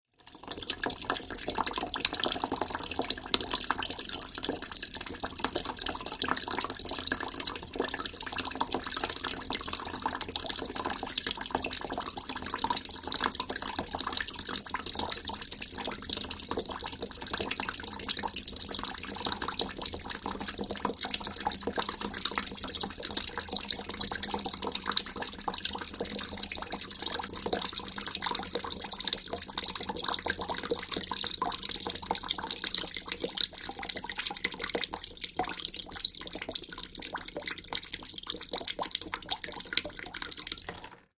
Water Trickle In A Plastic Basin